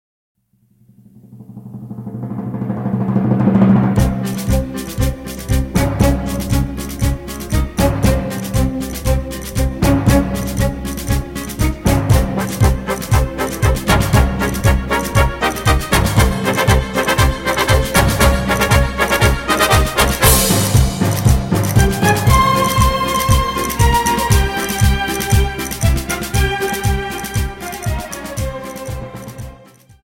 Dance: Paso Doble 60